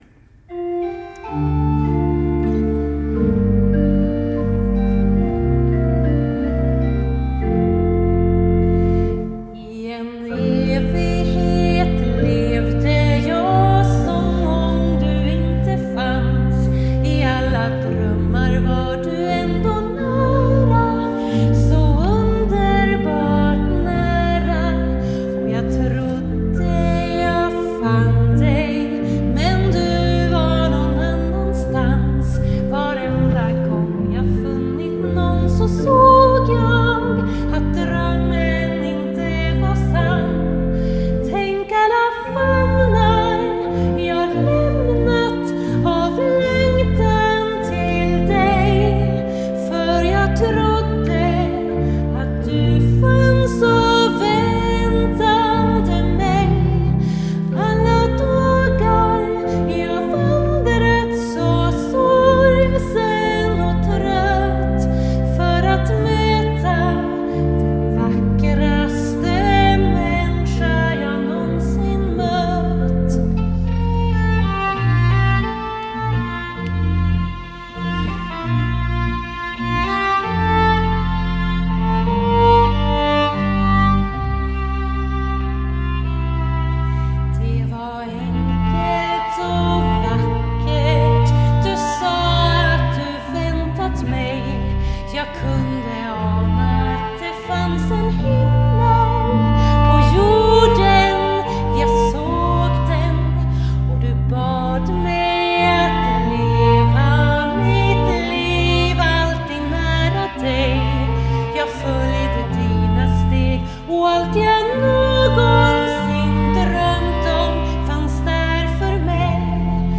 Sångerska